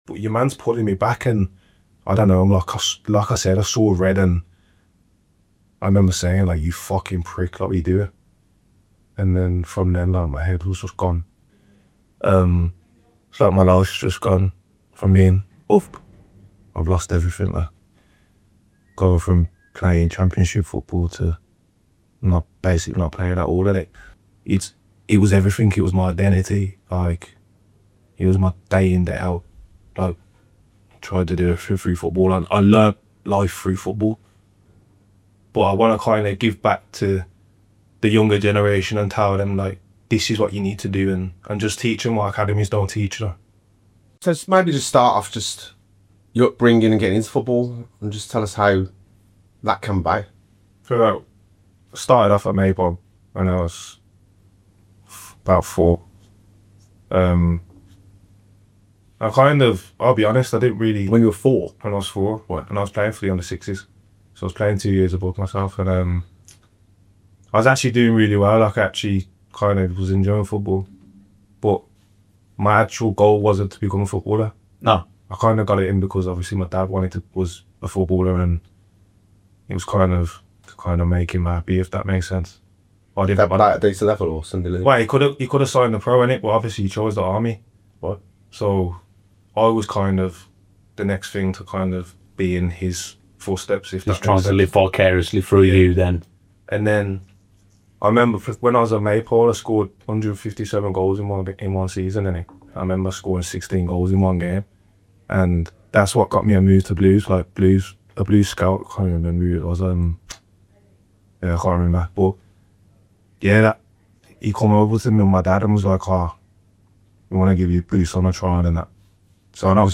It’s the story of what happens after the headlines stop. 🎙 B2B: Made in Brum — real conversations with people shaped by Birmingham and beyond.